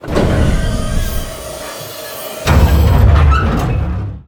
bayclose.ogg